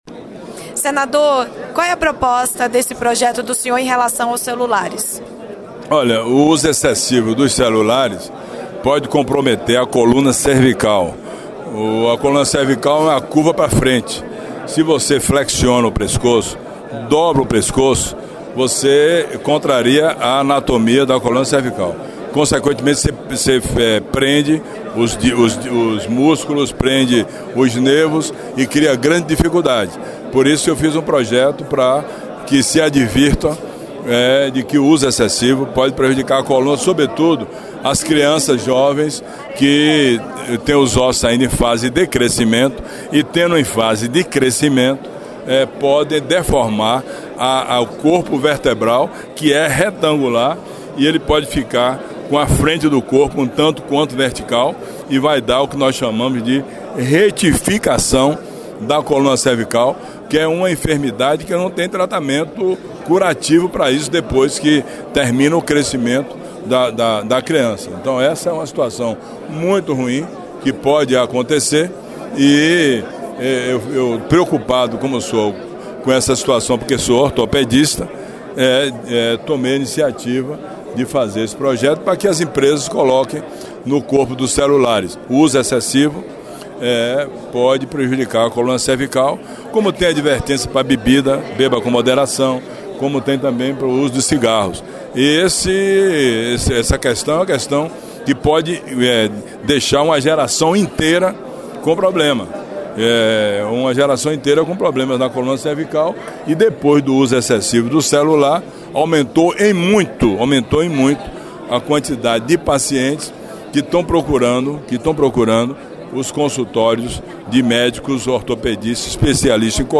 O uso incorreto dos aparelhos celulares tem provocado um aumento de problemas na coluna cervical dos usuários, principalmente das crianças que estão com os ossos em fase de formação. O alerta é feito pelo senador e ortopedista, Otto Alencar (PSD – BA).